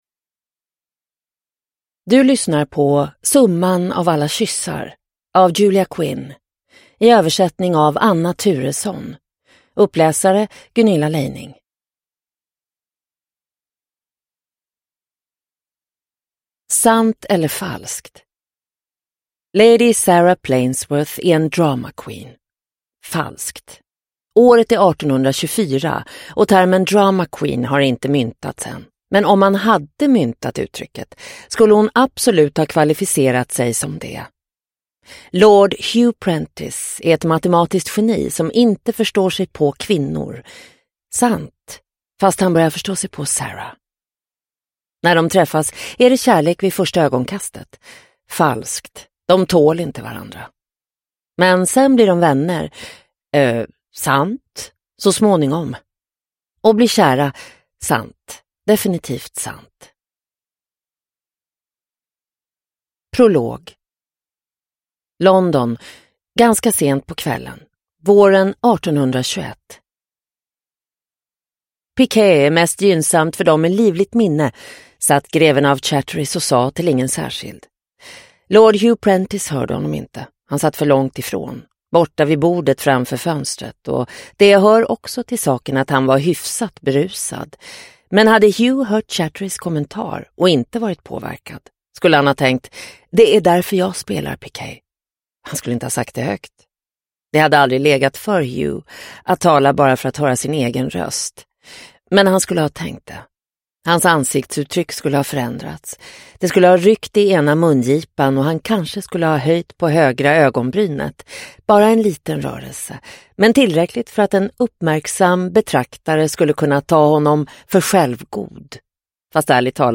Summan av alla kyssar – Ljudbok – Laddas ner